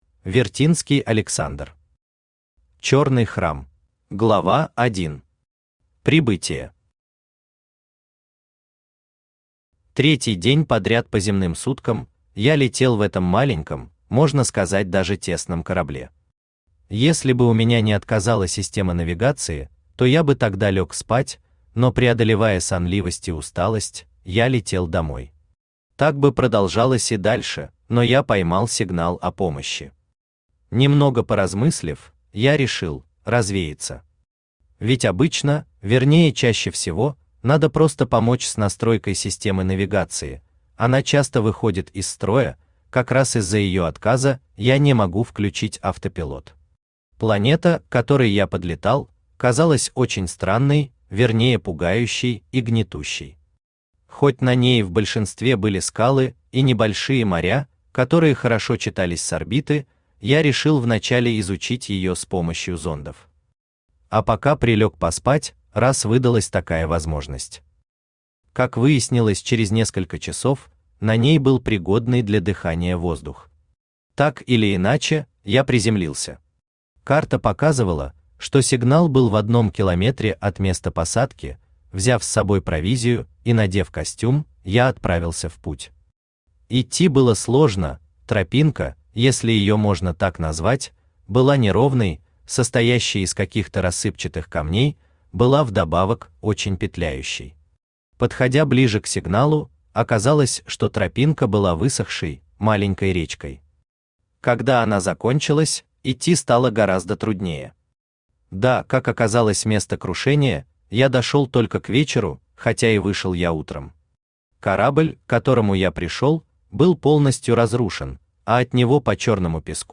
Aудиокнига Черный храм Автор Вертинский Александр Читает аудиокнигу Авточтец ЛитРес.